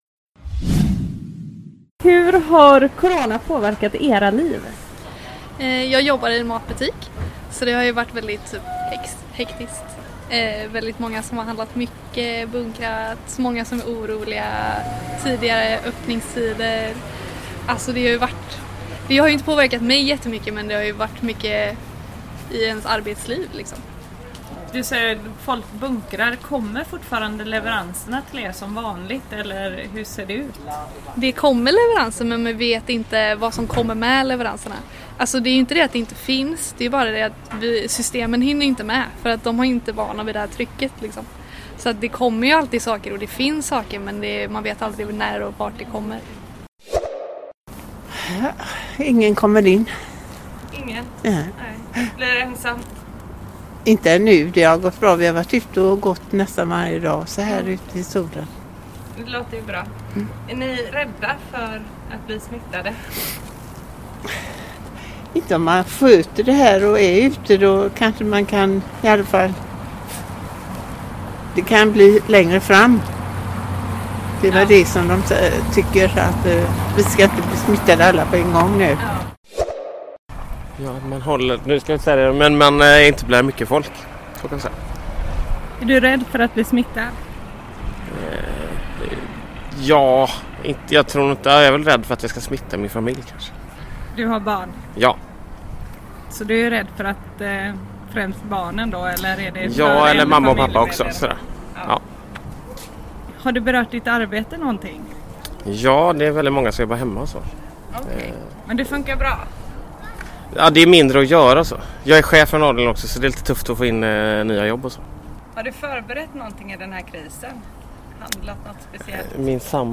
REPORTAGE: Hur har krisen påverkat vanligt folk?